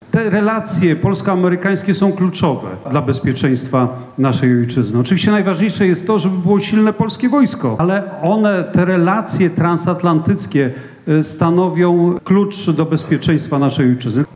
Przewodniczący klubu parlamentarnego PiS, były wicepremier Mariusz Błaszczak poparł w Białej Podlaskiej kandydaturę Karola Nawrockiego na prezydenta Polski.